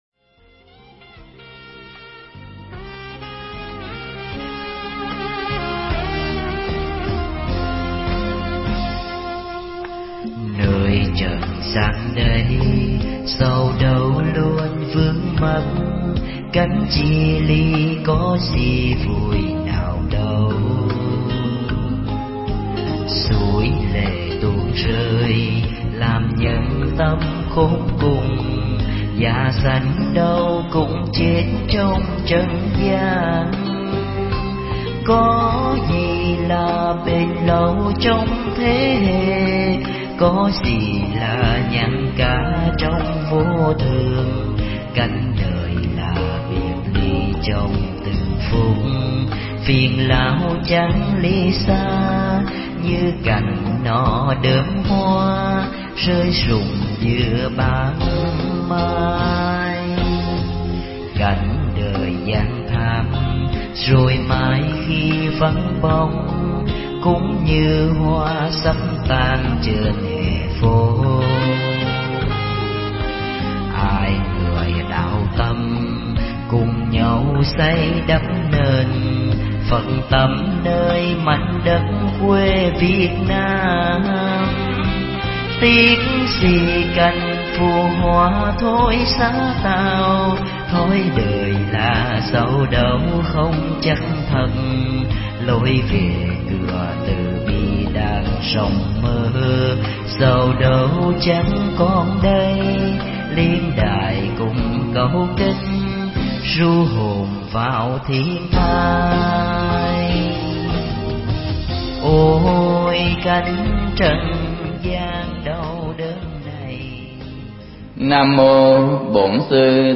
Mp3 Pháp Thoại Hoa Khai Kiến Phật Ngộ Vô Sanh
giảng tại Chùa Tân Lâm